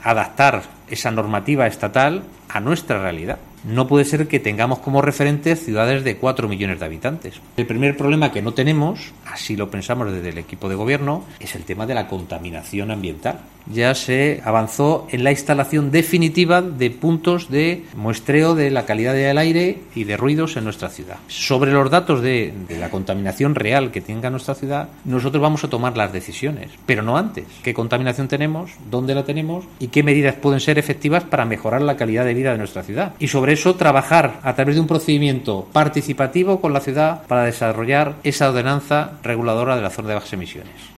Paco Cañizares, alcalde de Ciudad Real, sobre la supresión de la Zona Azul en las tardes de agosto